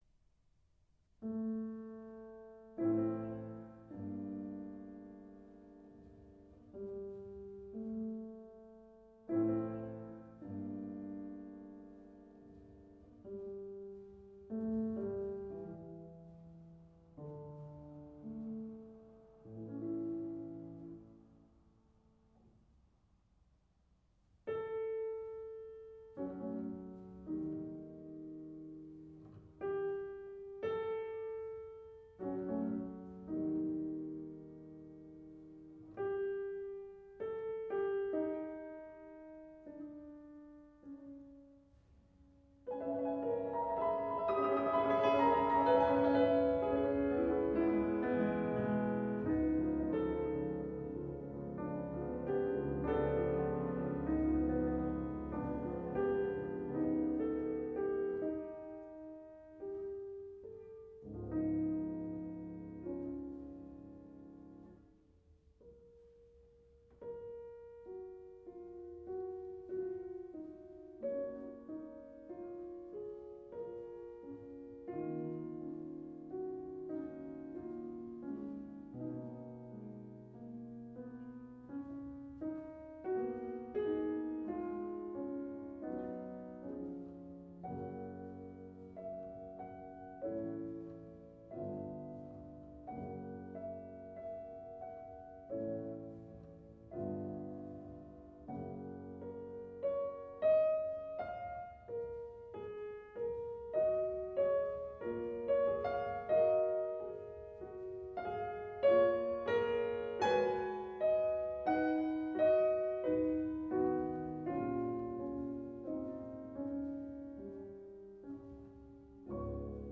String Quartet in F major
Très lent